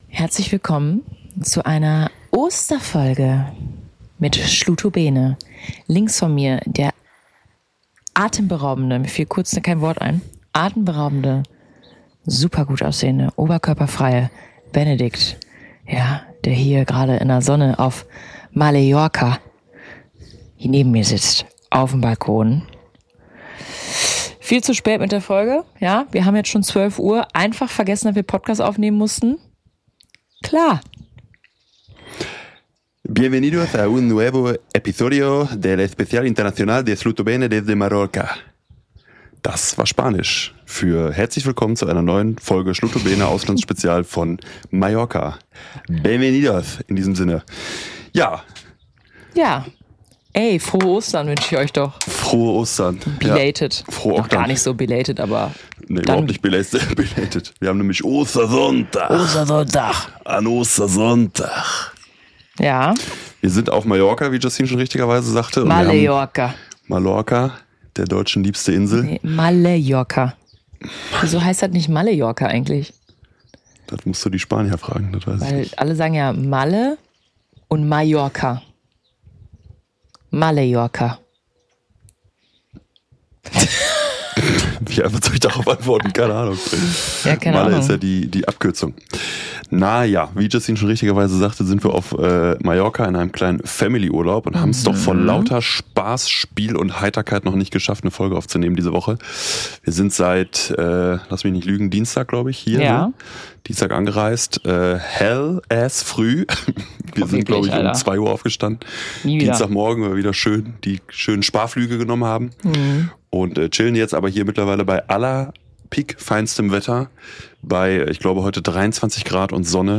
Die beiden Hosts sitzen auf der Veranda einer spanischen Finca mitten in einem Familienurlaub am Ostersonntag. Es geht um die Kapitel des Lebens, Midlife-Crisis für Mann und Frau und darum, wann man im Leben seinen Peak hat?